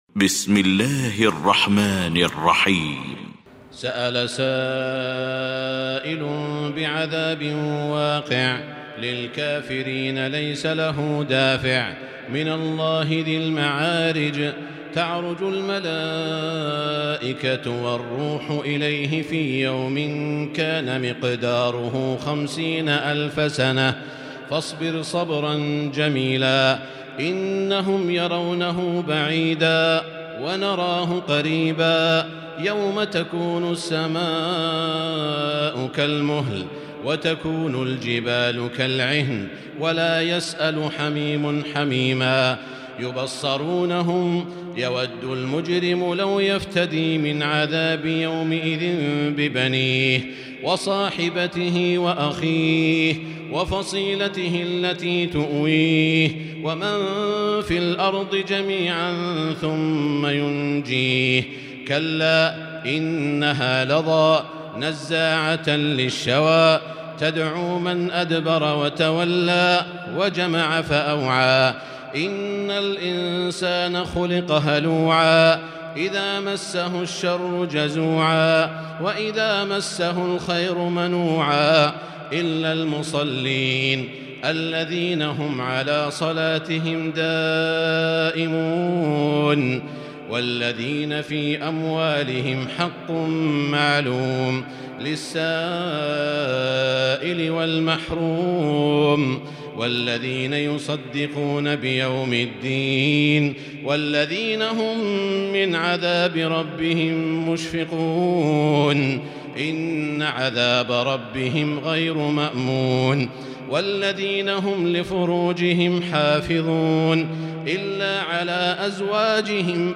المكان: المسجد الحرام الشيخ: سعود الشريم سعود الشريم المعارج